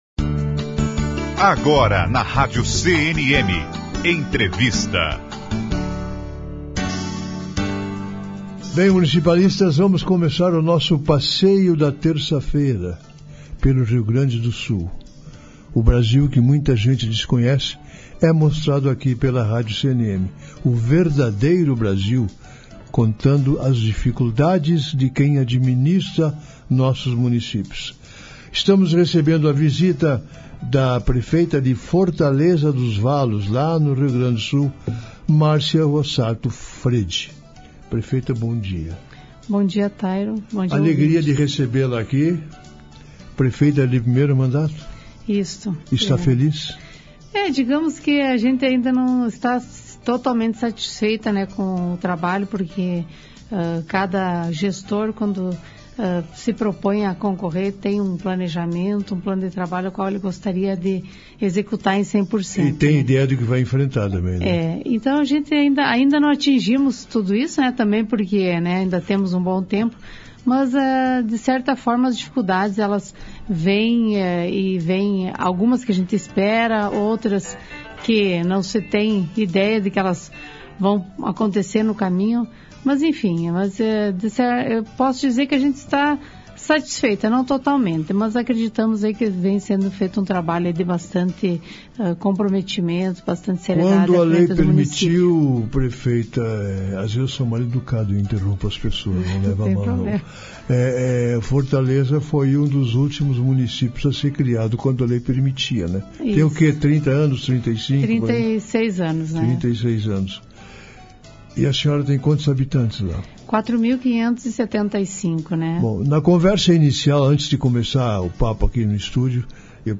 Entrevista - Prefeita Márcia Rossato Fredi - Fortaleza dos Valos (RS)
Entrevista---Prefeita-Mrcia-Rossato-Fredi---Fortaleza-dos-Valos-RS_.mp3